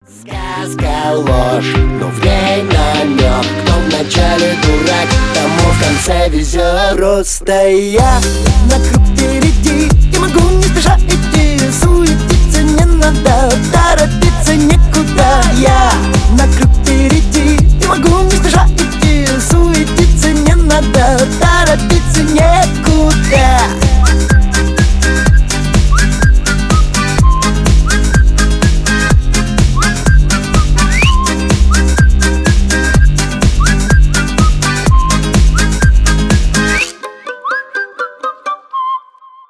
свист